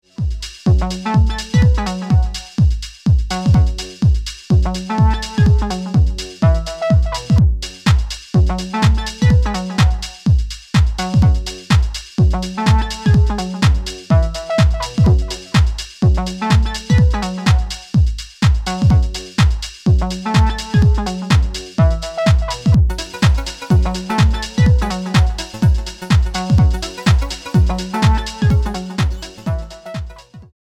five-track tech-house project